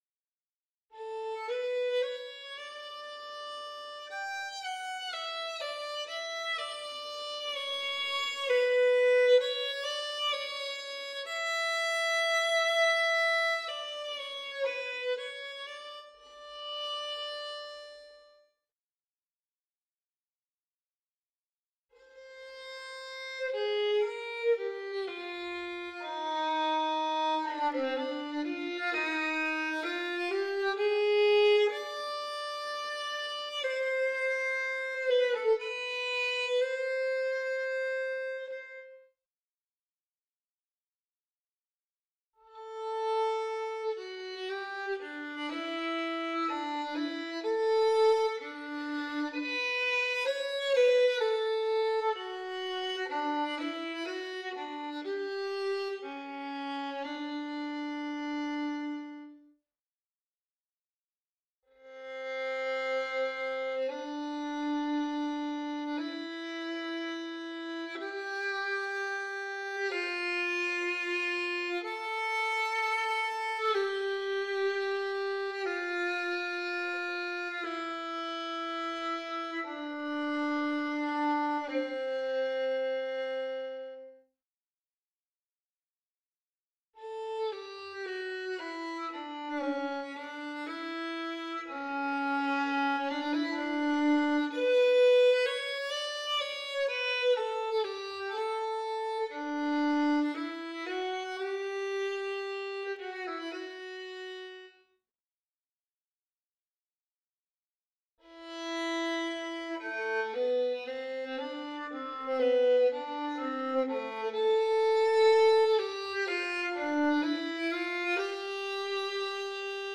good-cp5-05-Violin_0.mp3